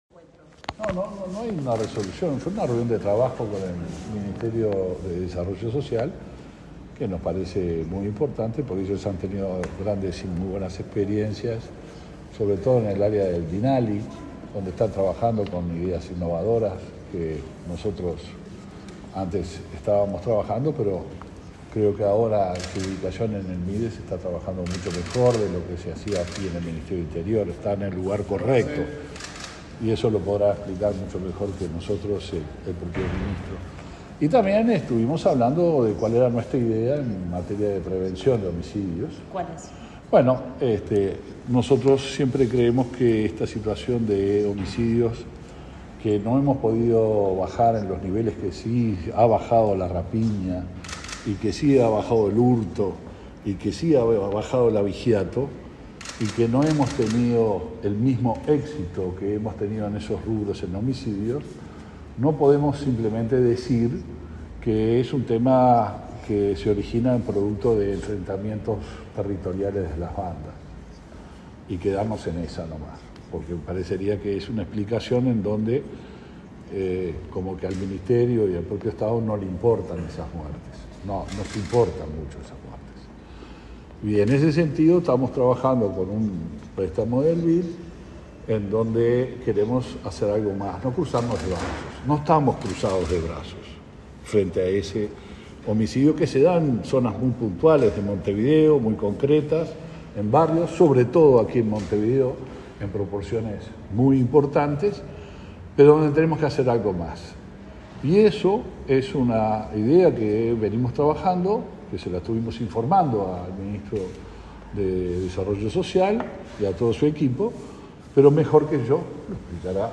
Declaraciones del ministro del Interior, Luis Alberto Heber